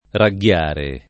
ragghiare [ ra ggL# re ]